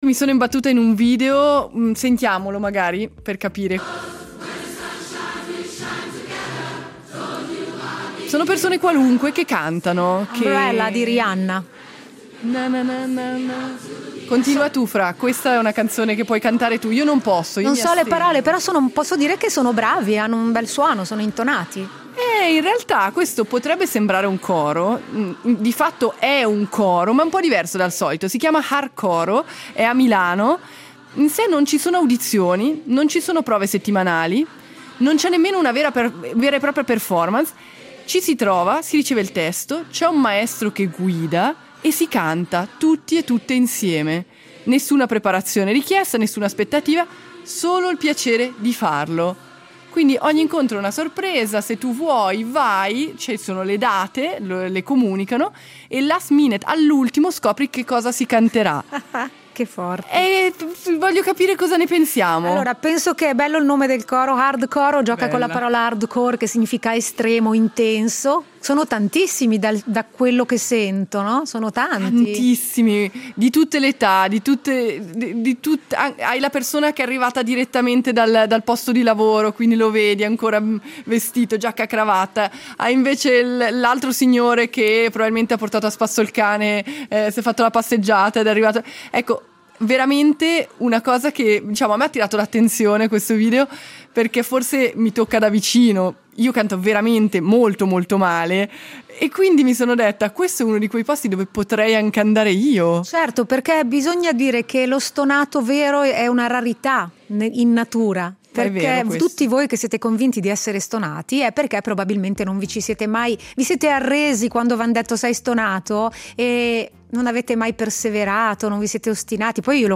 Coro
Niente audizioni, niente prove, niente performance. Solo persone normali che si incontrano, scoprono la canzone sul momento… e cantano.